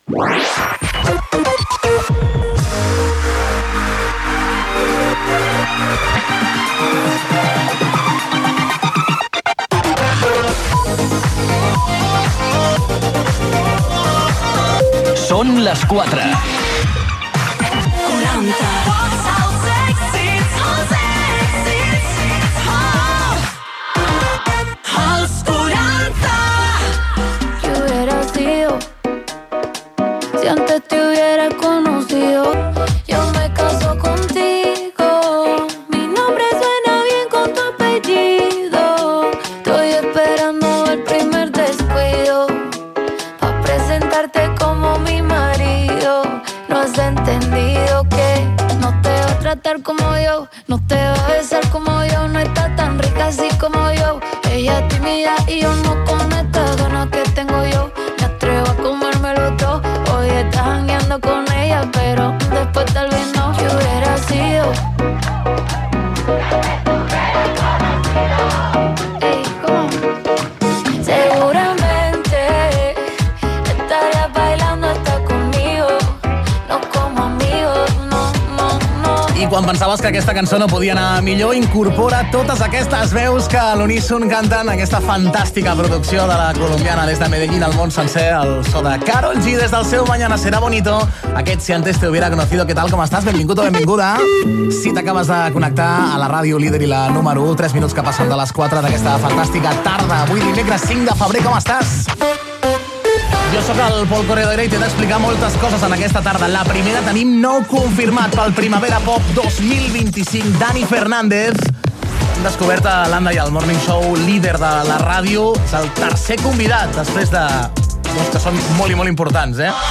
Indicatiu de la ràdio, tema musical, hora, data, benvinguda, indicatiu, tema musical, indicatiu, tema misical, comentari del tema que sonava, indicatiu, tema musical
Musical
FM